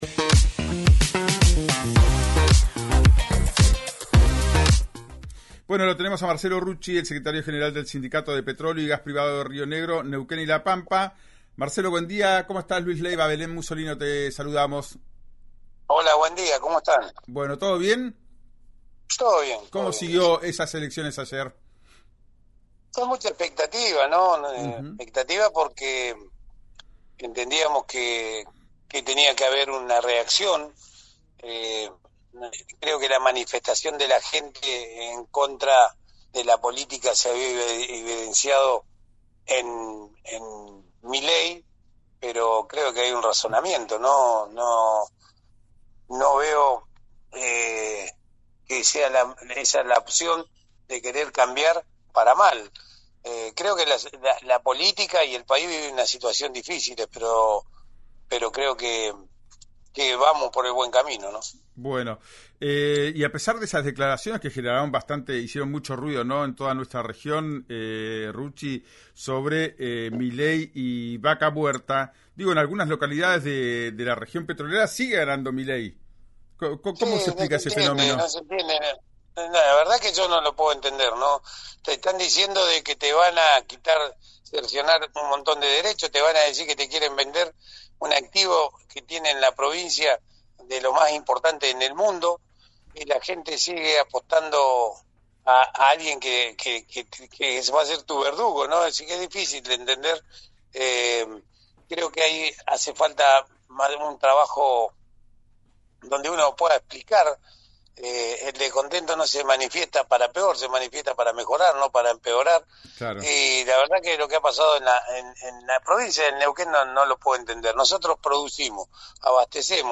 En diálogo con RÍO NEGRO RADIO, el dirigente petrolero y exintendente de Rincón de los Sauces dijo tener «bronca» con los resultados en esa localidad y Añelo, donde Milei obtuvo más de un 50%.
Escuchá a Marcelo Rucci, secretario general de Petroleros Privados, en RÍO NEGRO RADIO: